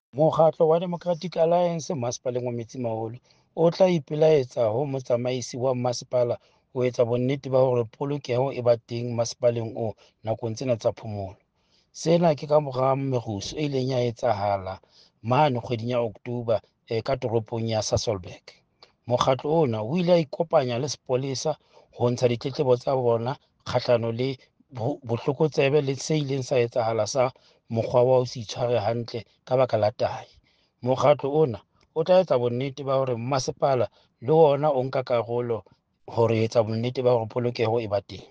Sesotho soundbite by Cllr Stone Makhema.